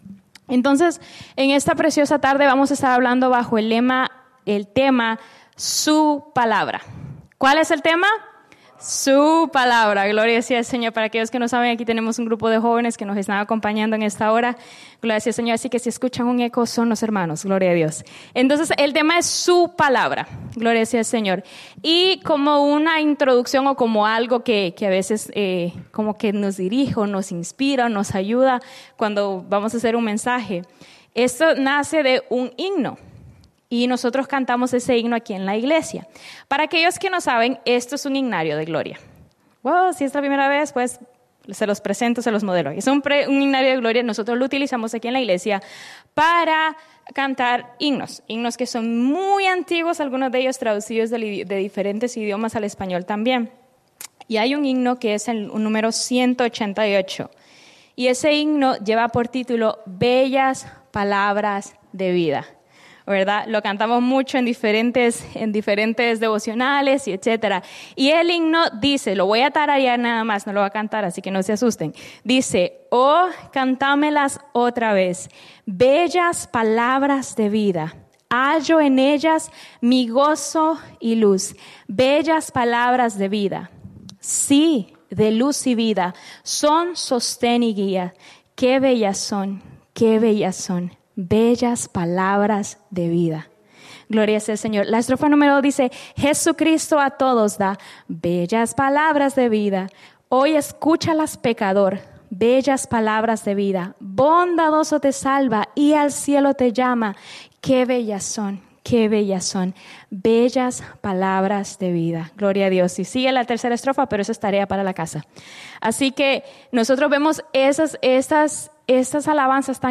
Mensaje
en la Iglesia Misión Evangélica en Souderton, PA